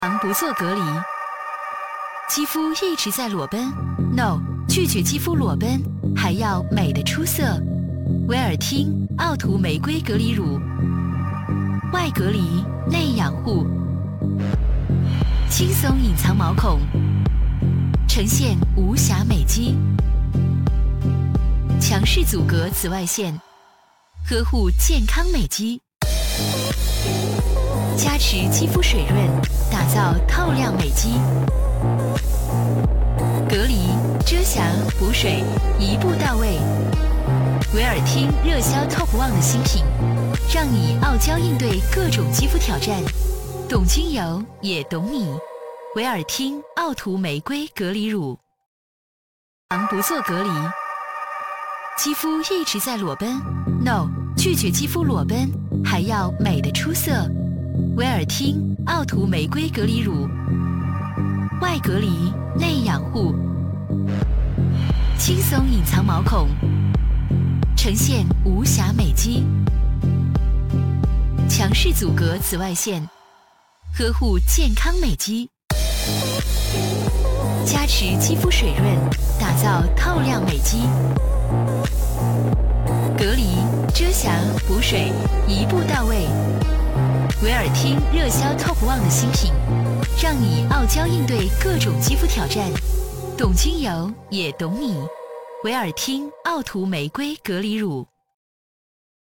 国语青年积极向上 、亲切甜美 、女课件PPT 、工程介绍 、旅游导览 、80元/分钟女S112 国语 女声 课件-下颌骨重建 解说词 积极向上|亲切甜美